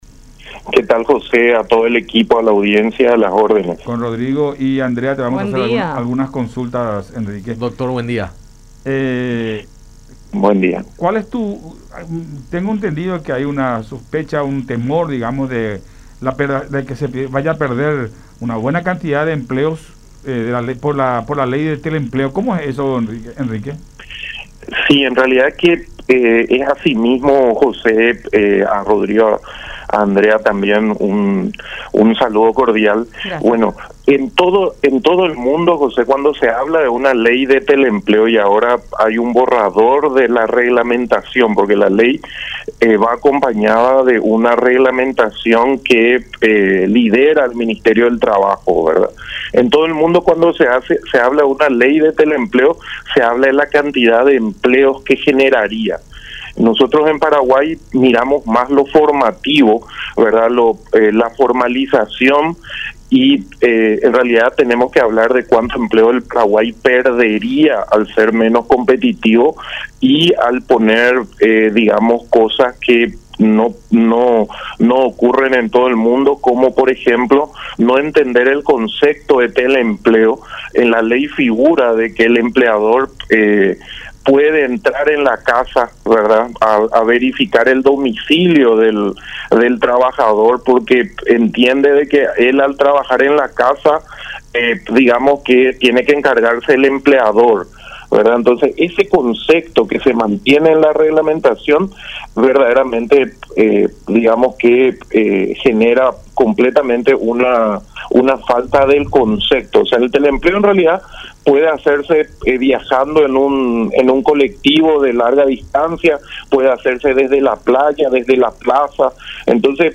en diálogo con Enfoque 800 por La Unión